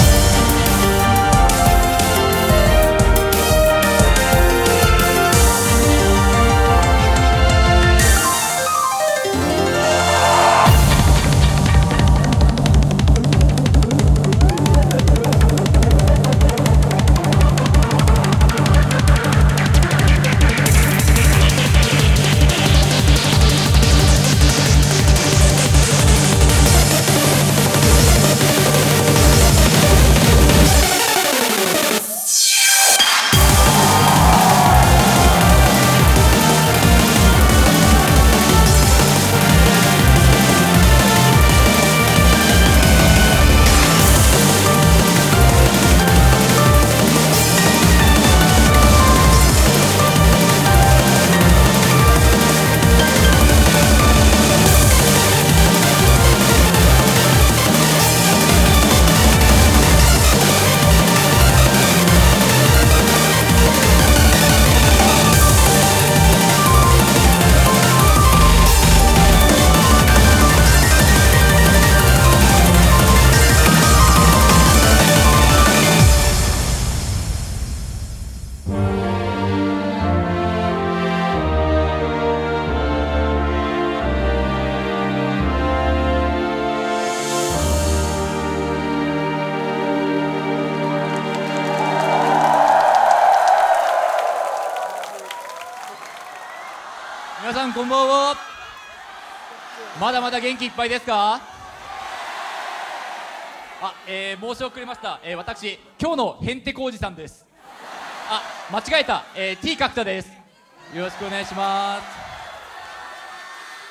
Audio QualityPerfect (Low Quality)